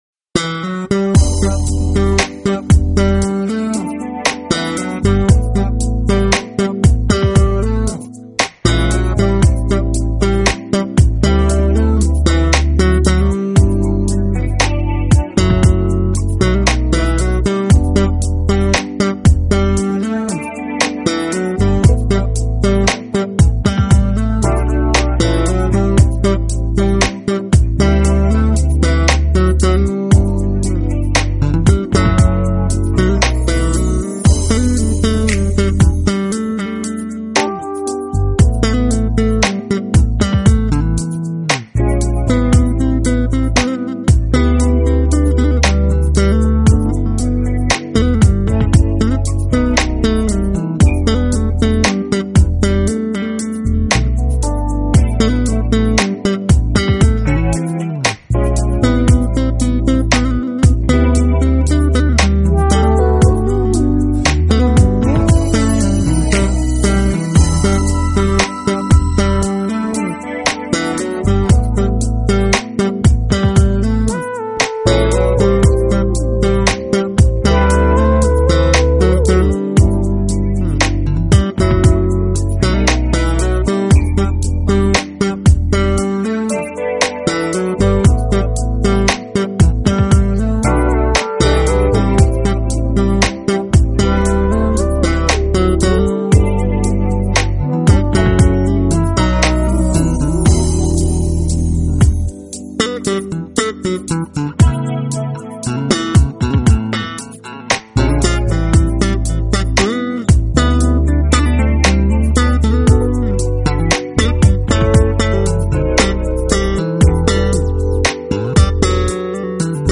Genre: Jazz Bass, Jazz Funk